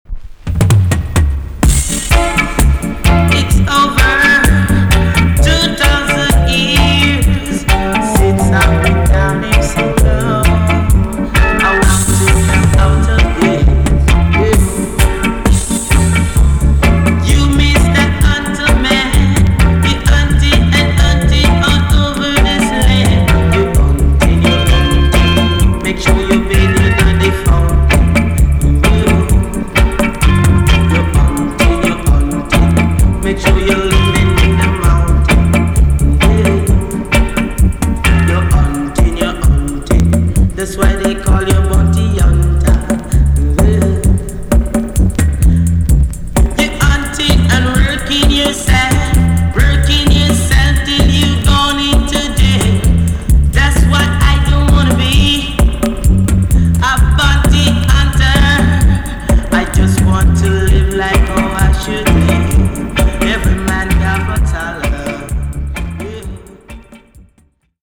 TOP >LP >80'S 90'S DANCEHALL
B.SIDE EX 音はキレイです。